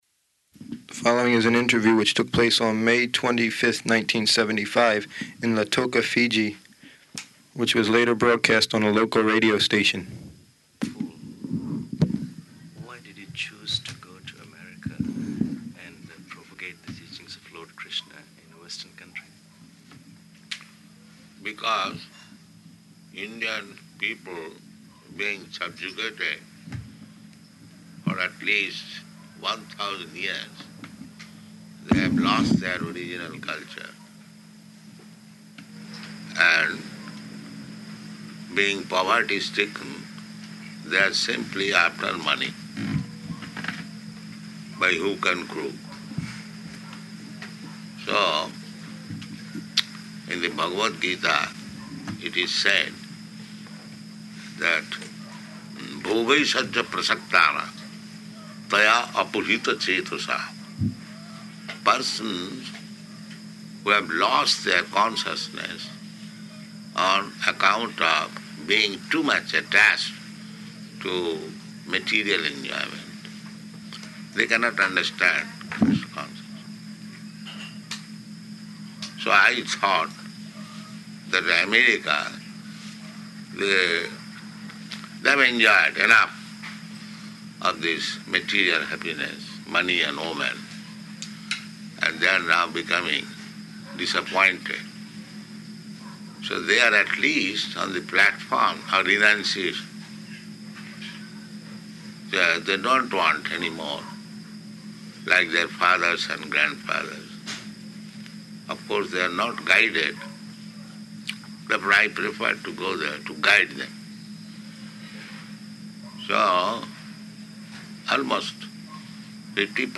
Radio Interview
Type: Interview
Location: Fiji